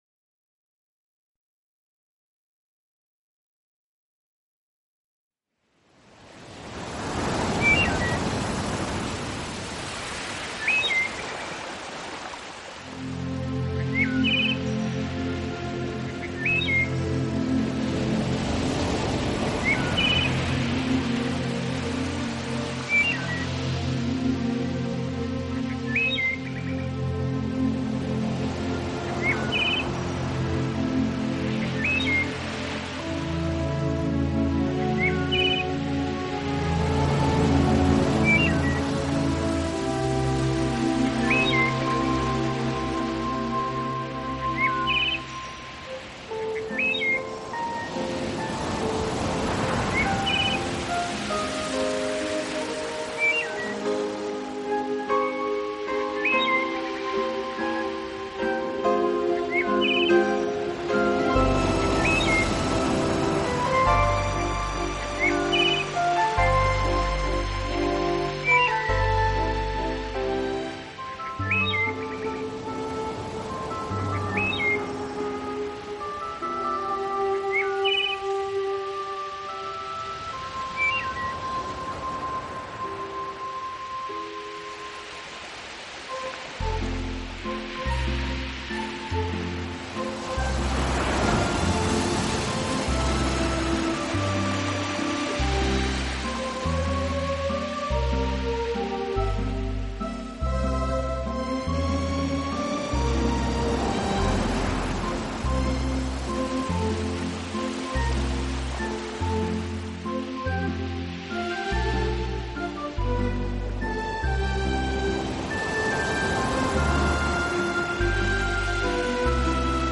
自然聲響與音樂的完美對話
新世紀的音樂形式使躁動的靈魂得到最溫柔的撫慰，你將在夜裡
海浪、流水、鳥鳴，風吹過樹葉，雨打在屋頂，
大自然的原始採樣加上改編的著名樂曲合成了天籟之音。